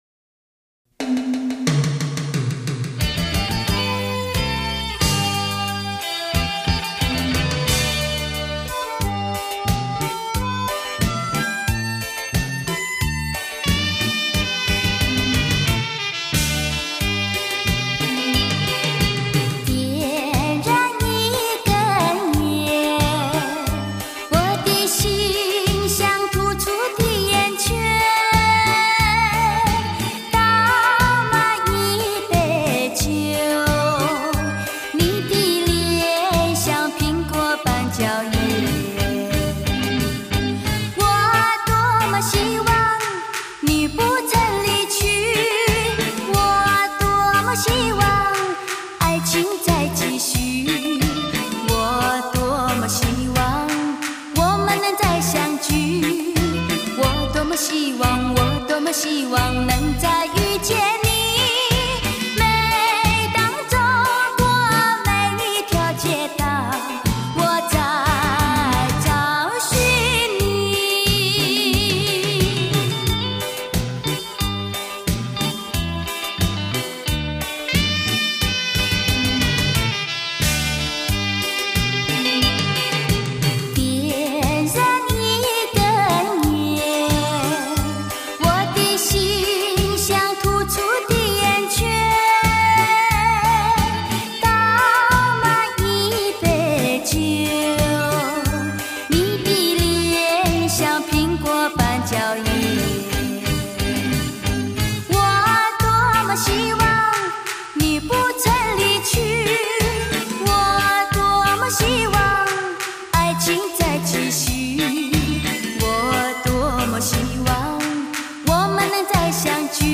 她的嗓音犹如天籁，她的歌感伤而真挚，让人感动。她的歌犹如出谷黄莺，百听不厌。